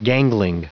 Prononciation du mot gangling en anglais (fichier audio)
Prononciation du mot : gangling